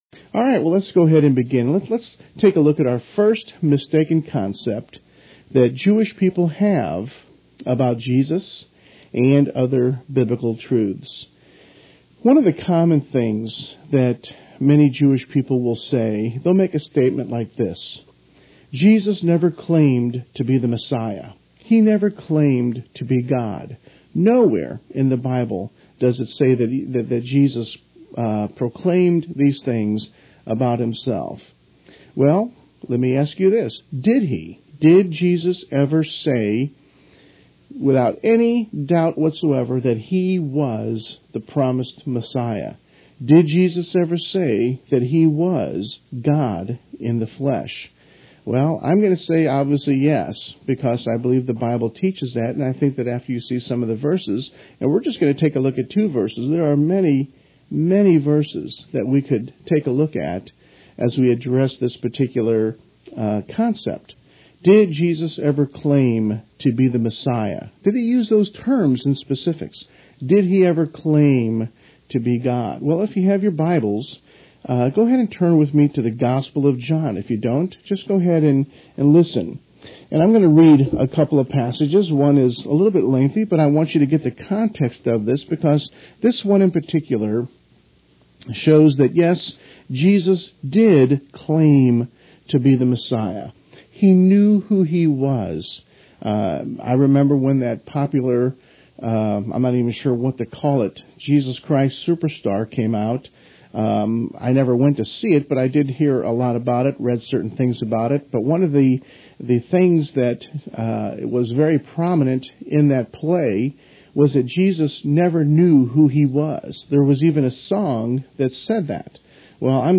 Radio Ministry
radio-broadcast.wav